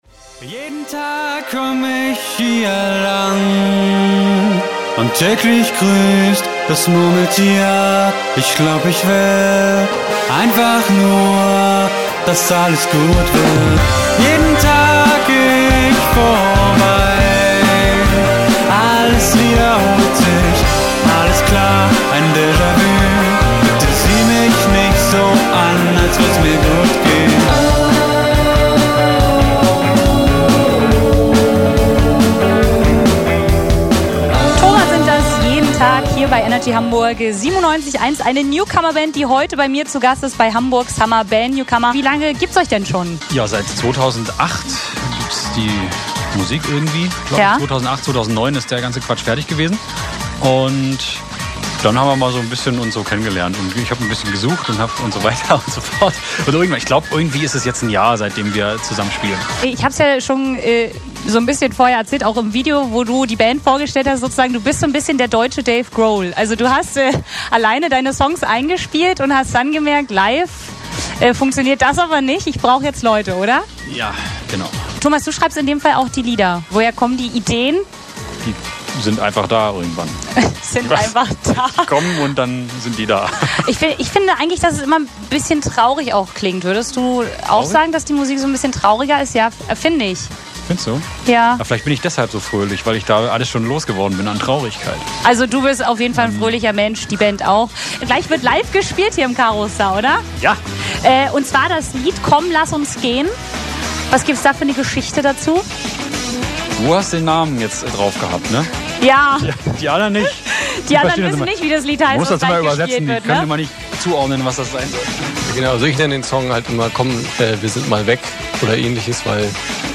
Das Interview bei Radio Energy gibts für alle, die es verpasst haben übrigens auch als Zusammenschnitt:
NRJ_Interview_Zusammenschnitt_song_snippets.mp3